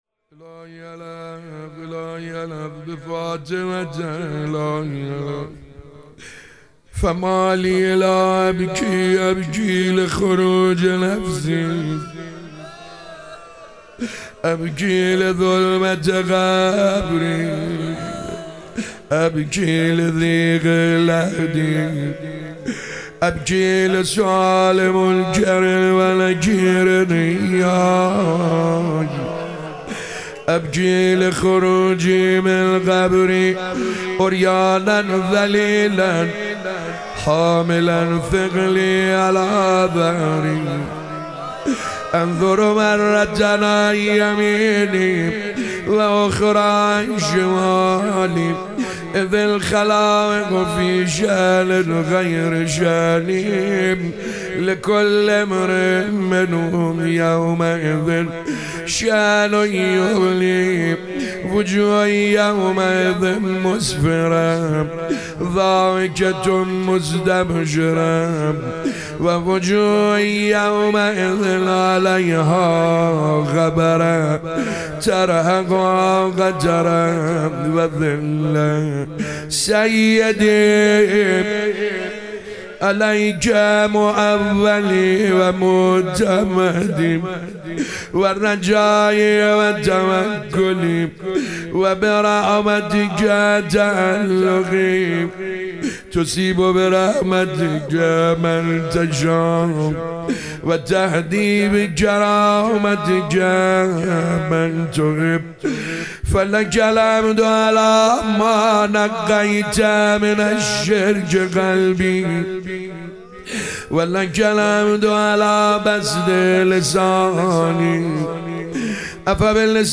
شب 26 رمضان 97 - مناجات خوانی - ابو حمزه ثمالی